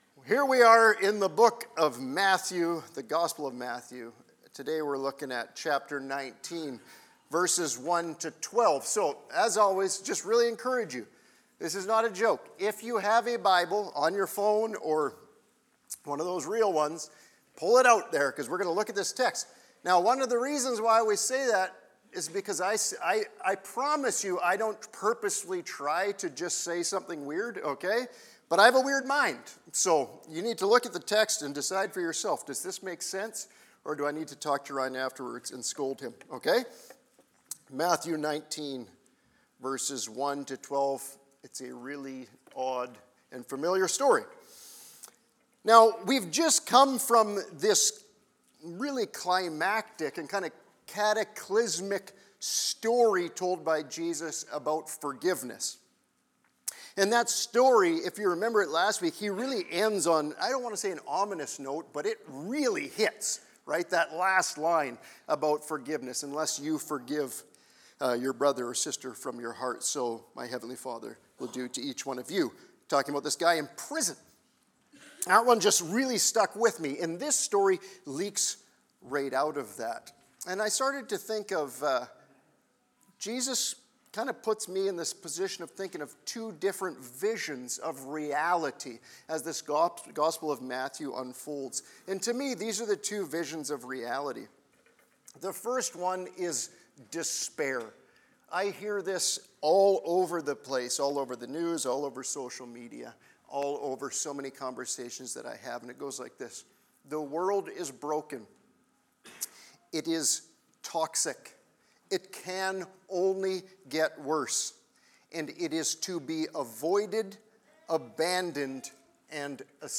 Sermon-Audio-Feb-1-2026.mp3